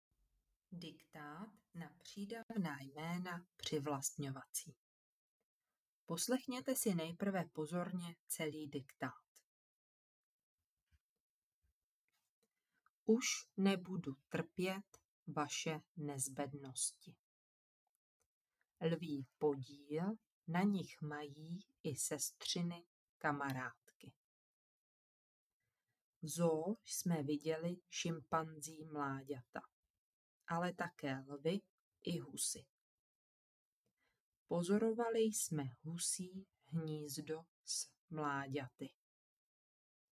V tomto dílu se zaměřujeme na přídavná jména přivlastňovací – opakování a upevnění gramatického jevu, který dělá studentům 5. třídy často potíže. Diktáty odpovídají osnovám, zvuky jsou čisté a srozumitelné, tempo vhodné pro děti.
ukazka-diktaty-5rocnik-pridavna-jmena-privlastnovaci-2.mp3